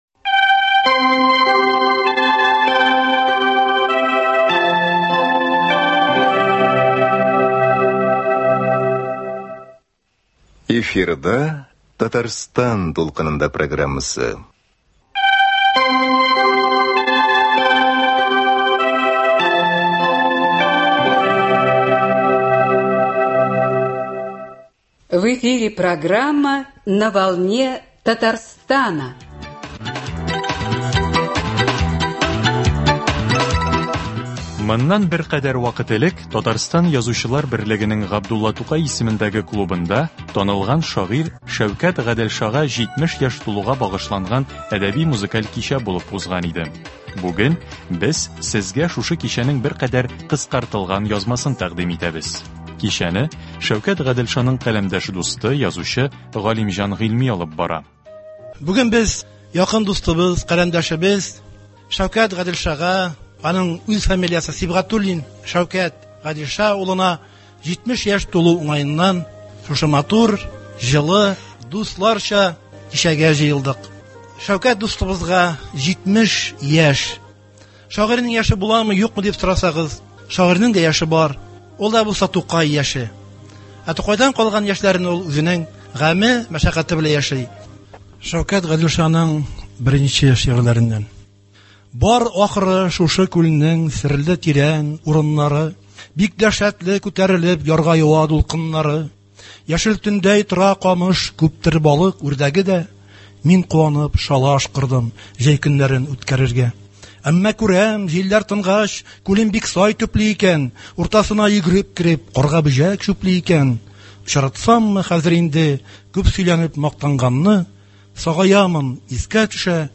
“Татарстан дулкынында” бу чыгарылышында Сез шагыйрь Шәүкәт Гаделша иҗатына багышланган кичәдән язма тыңлый аласыз.